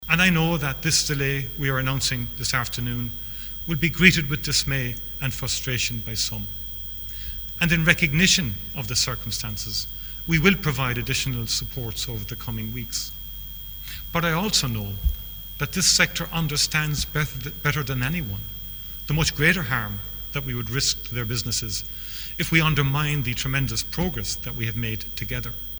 Taoiseach Michael Martin says the government wants to make sure that when a sector reopens, it stays open………